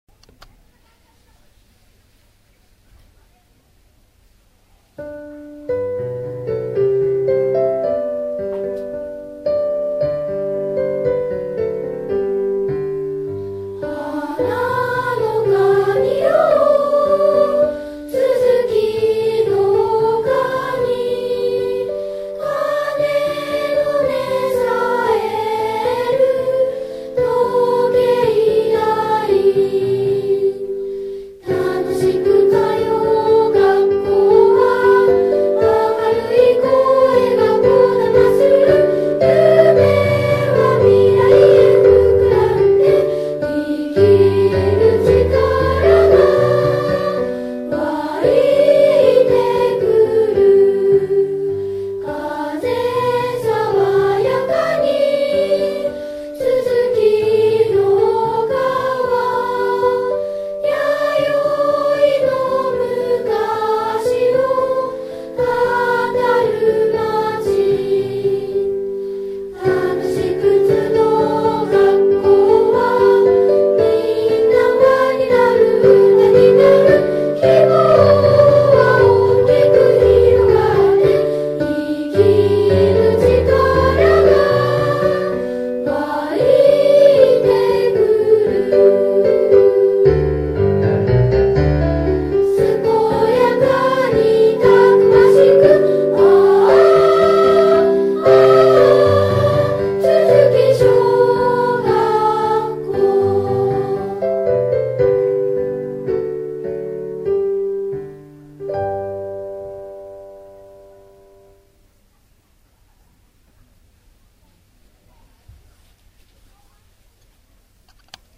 校歌
さわやかな旋律の中に、子どもたちの夢が未来へ、希望が大きく広がって、自分の力で自分の考えで、心豊かでたくましく、お互いを思いやる心をもった人に育ってほしいという願いが込められています。（校歌制定委員会）